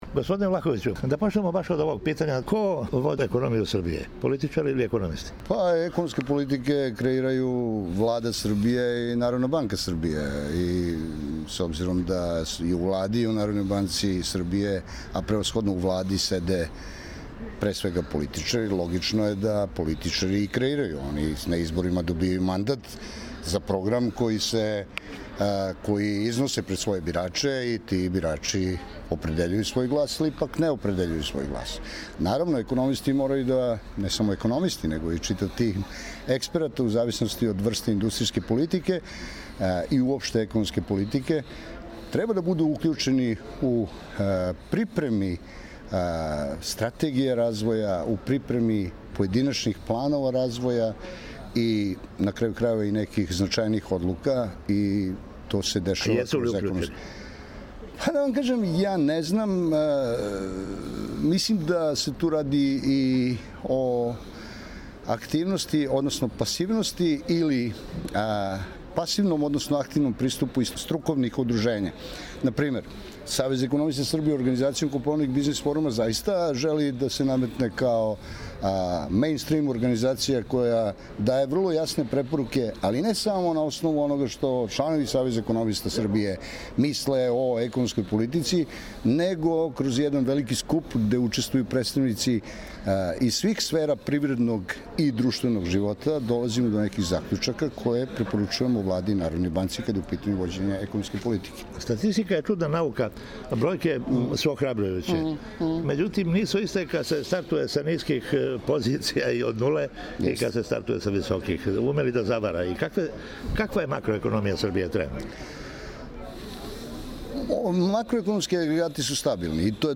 Ексклузиван интервју са Александром Влаховићем председником Савеза економиста Србије.